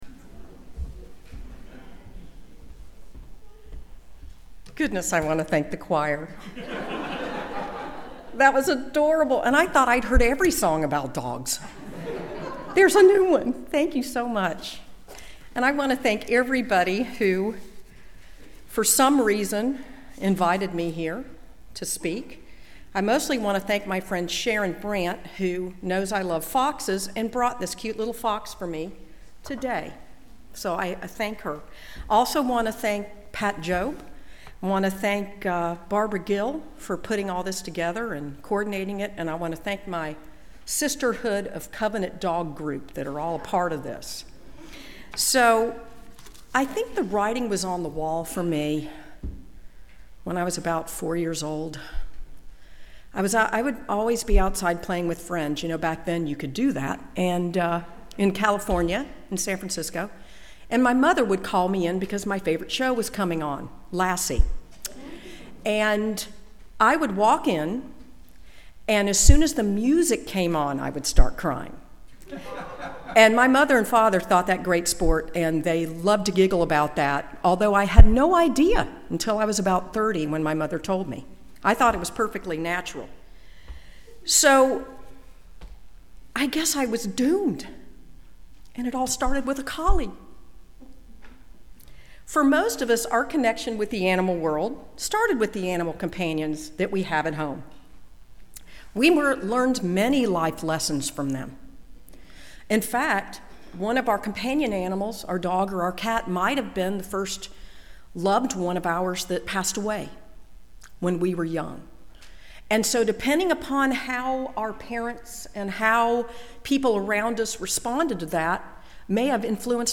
A Unitarian Universalist worship service about finding joy in the midst of pain and difficulty.